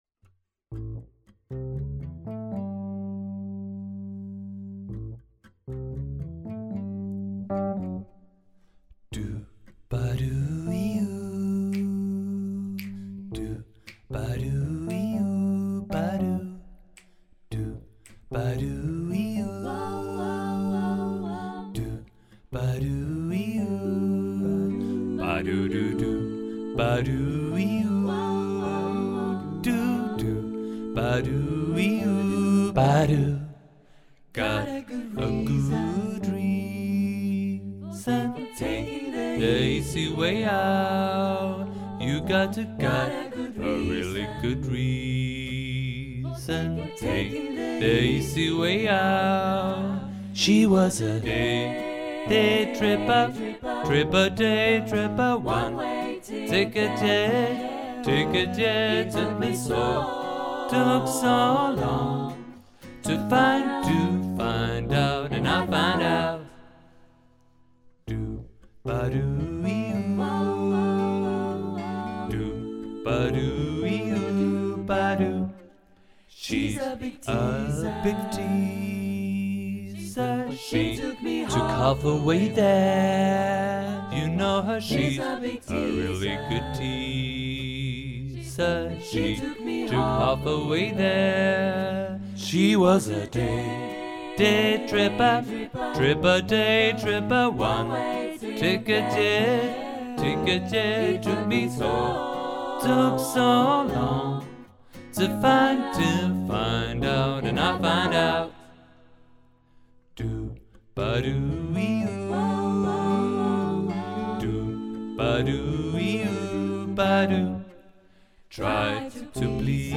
för 4-stämmig blandad kör